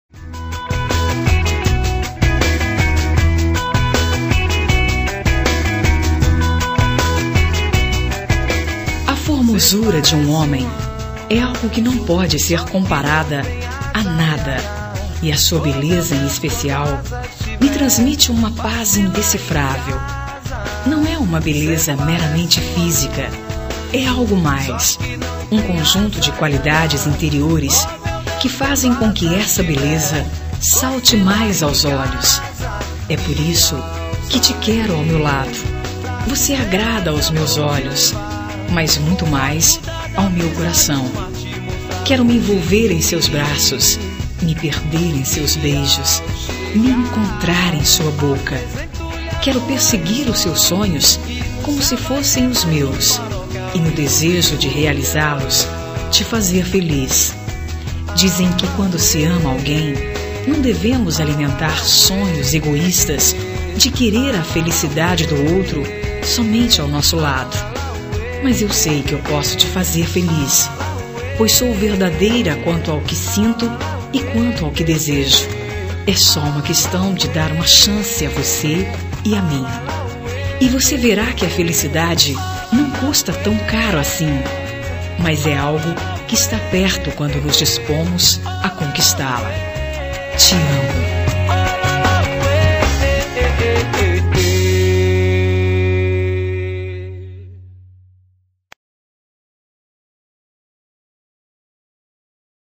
Telemensagem de Conquista – Voz Feminina – Cód: 140106